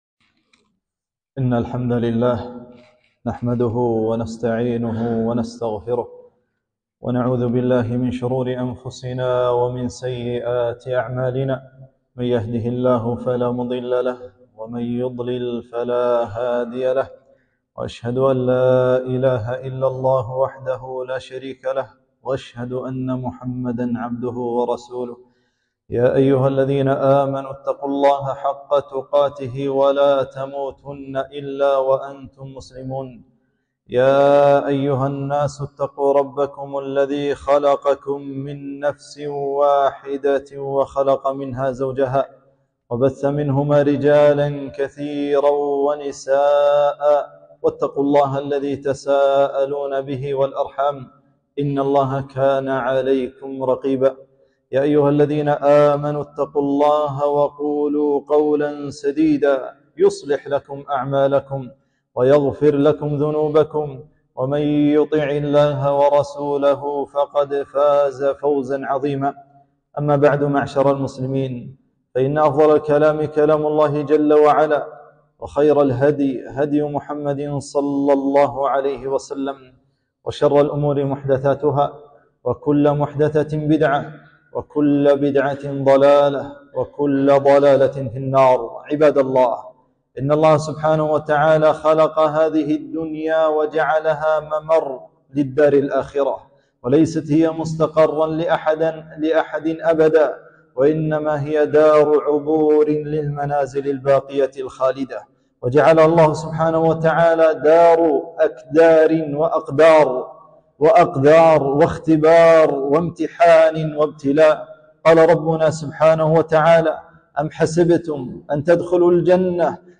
خطبة -